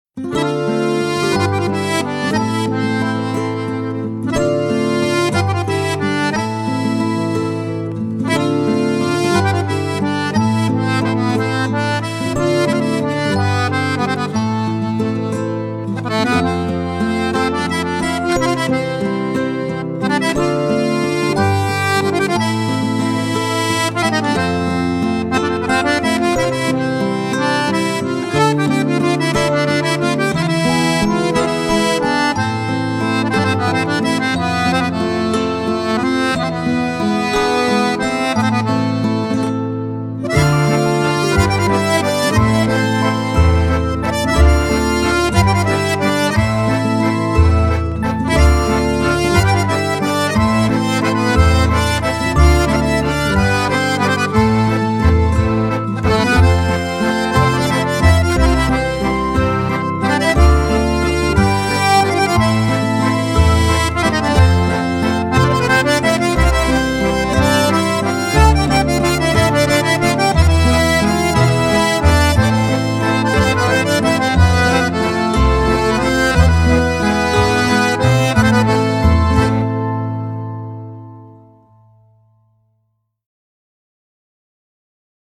موسیقی متن سریال
موسیقی بی‌کلام